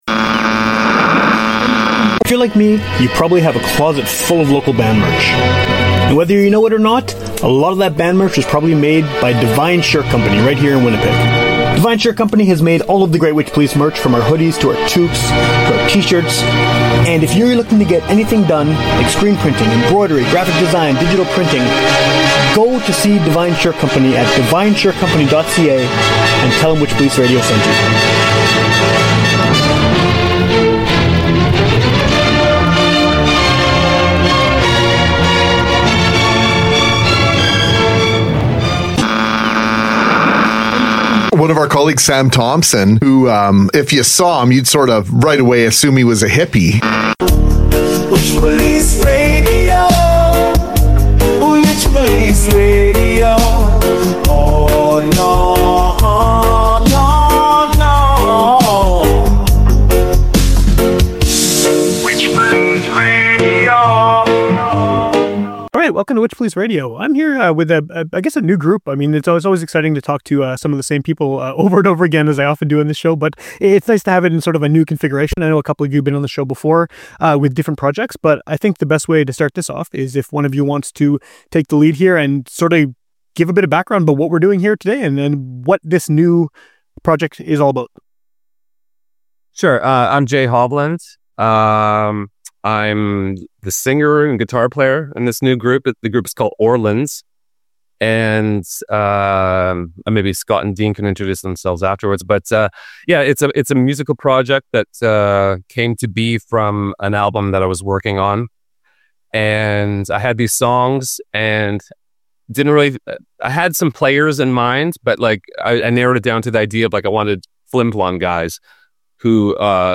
Tune in for a conversation about the record, their collaborative process, and more!